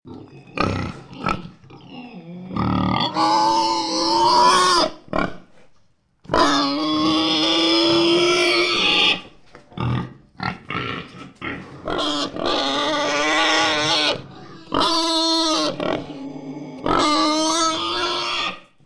Le cochon | Université populaire de la biosphère
il grogne et grouine
Le cochon grogne.
cochon.mp3